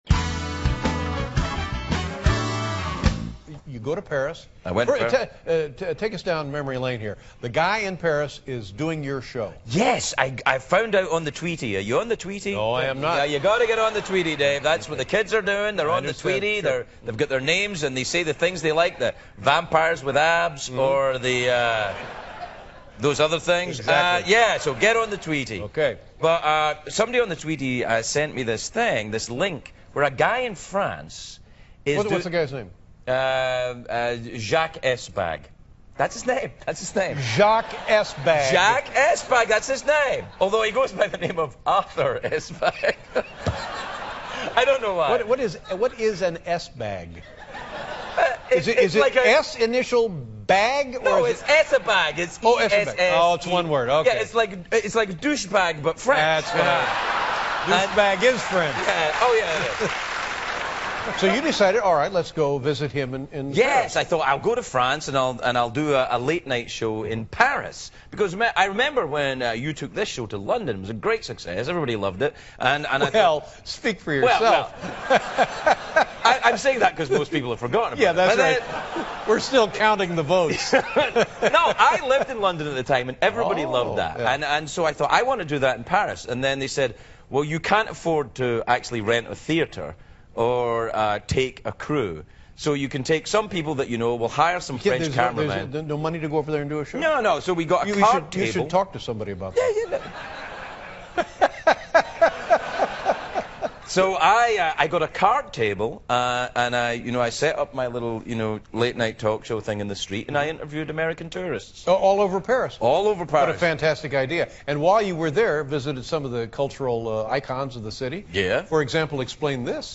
访谈录 2011-08-02&08-04 王牌对决：大卫·莱特曼访问 听力文件下载—在线英语听力室